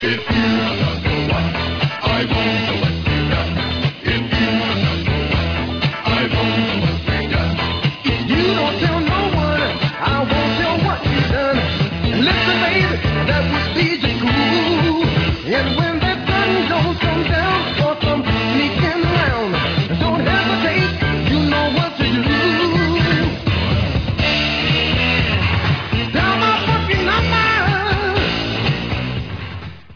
Background vocals, and guitar